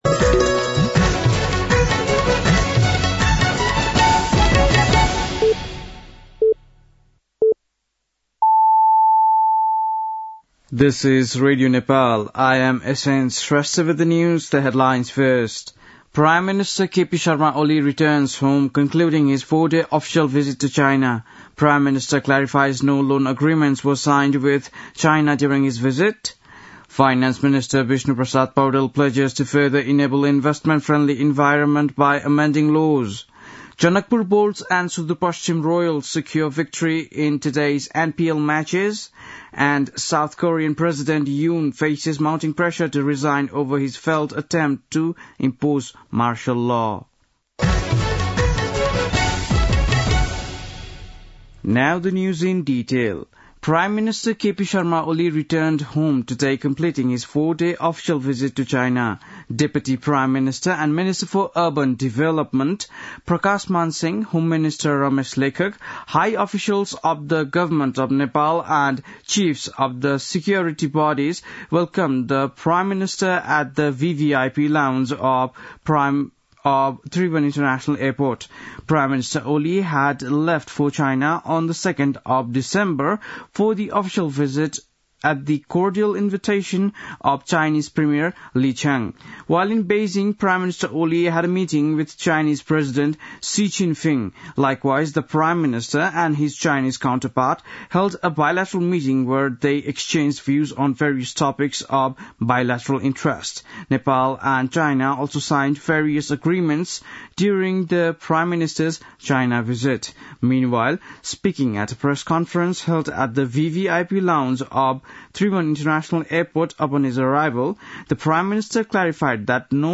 बेलुकी ८ बजेको अङ्ग्रेजी समाचार : २१ मंसिर , २०८१
8-pm-news-8-20.mp3